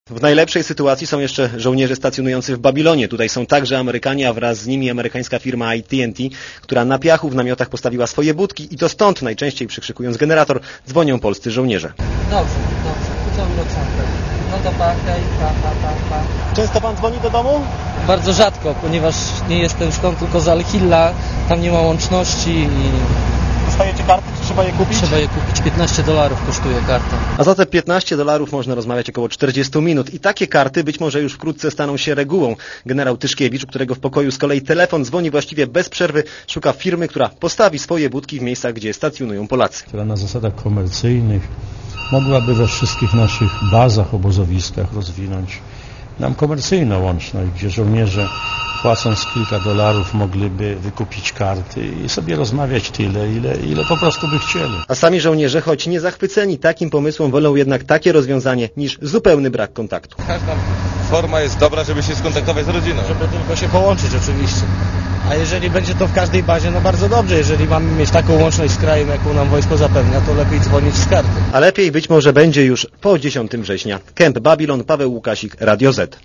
Posłuchaj relacji z Iraku (300 KB)